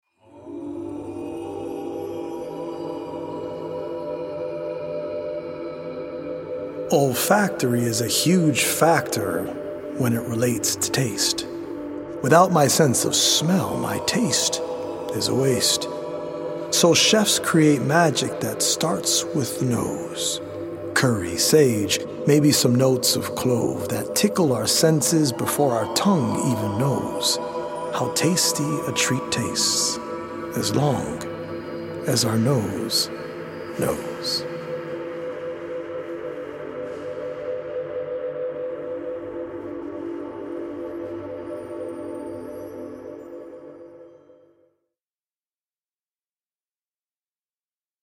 audio-visual poetic journey
healing Solfeggio frequency music